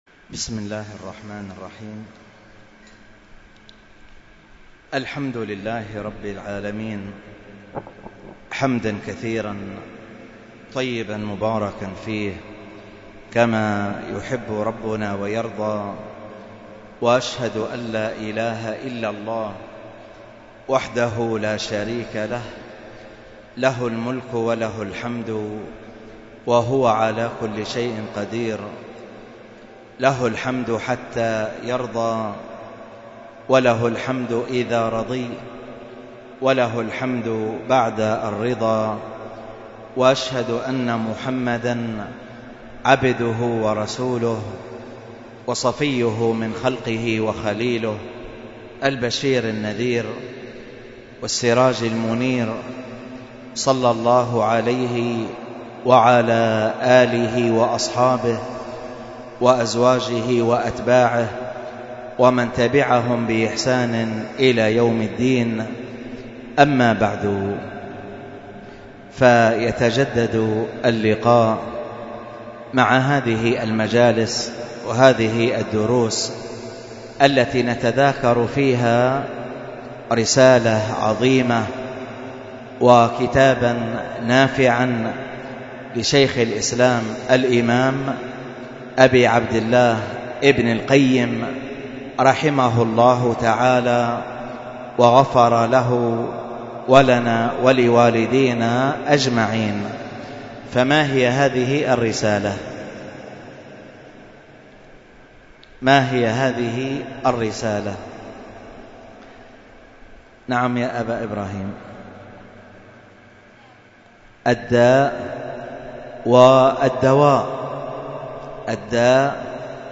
الدرس في التعليق على كتاب الداء والدواء 14